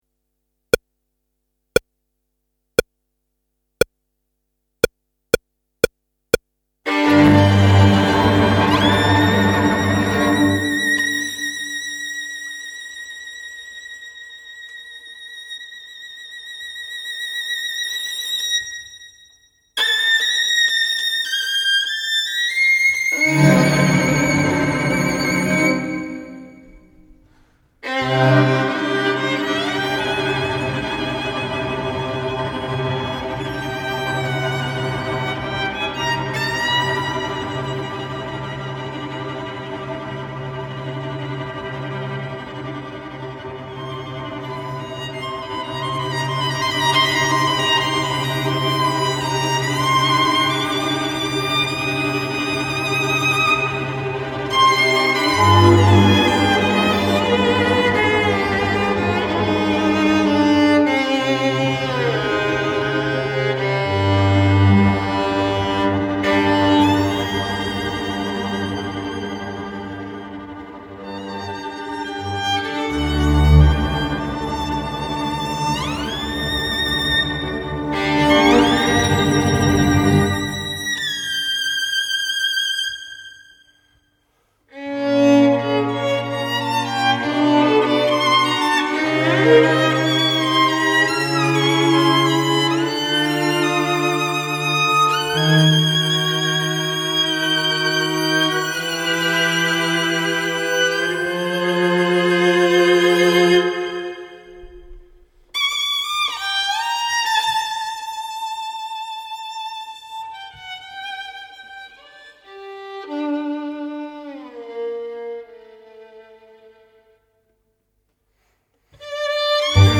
Violin Theme